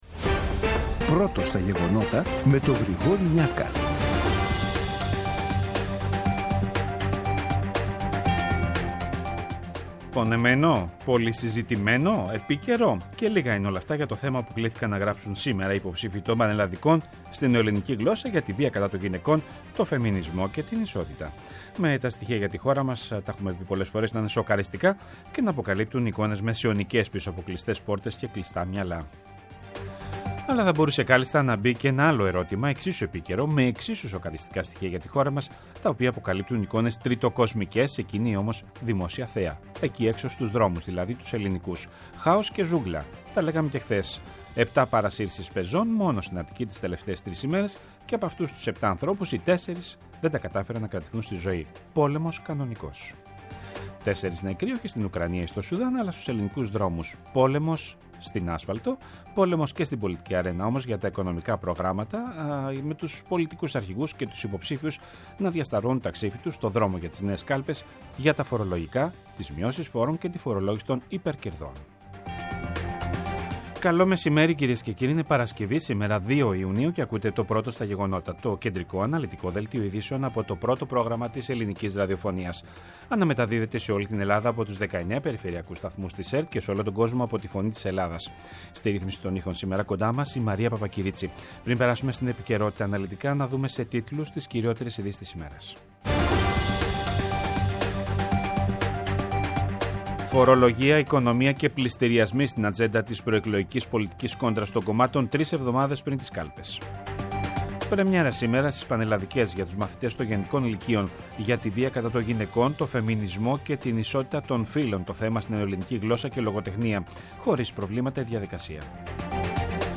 Το κεντρικό ενημερωτικό μαγκαζίνο του Α΄ Προγράμματος, από Δευτέρα έως Παρασκευή στις 14.00. Με το μεγαλύτερο δίκτυο ανταποκριτών σε όλη τη χώρα, αναλυτικά ρεπορτάζ και συνεντεύξεις επικαιρότητας.